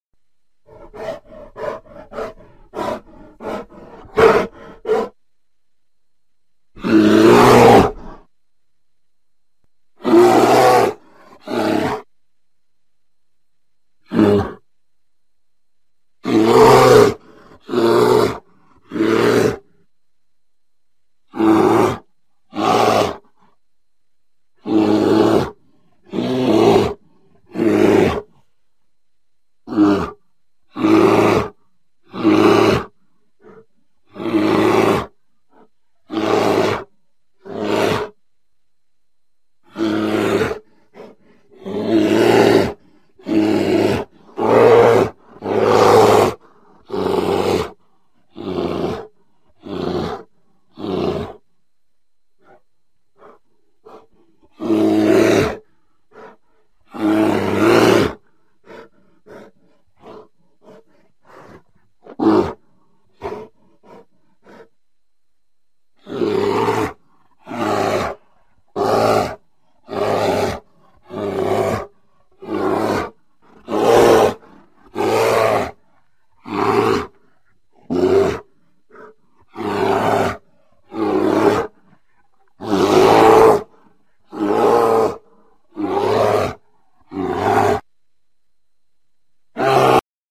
Sonido de Osos - Sonidos de Animales.mp3